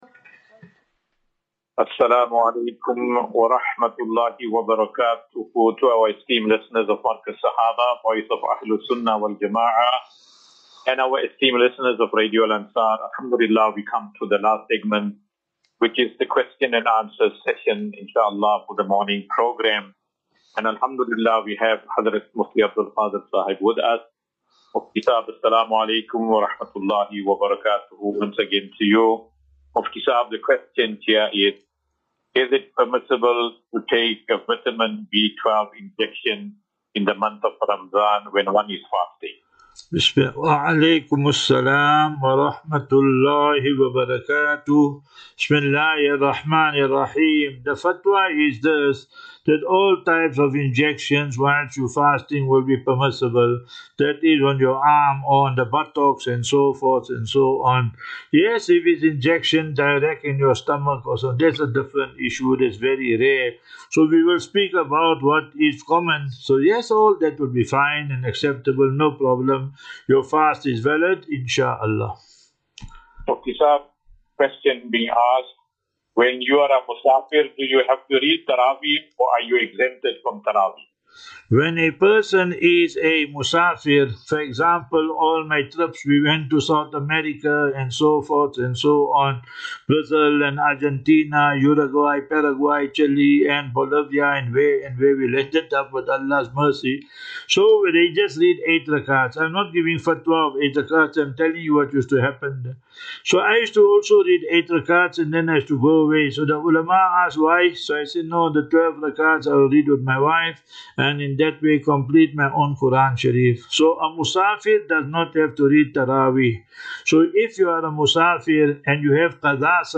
View Promo Continue Install As Safinatu Ilal Jannah Naseeha and Q and A 7 Mar 07 March 2025.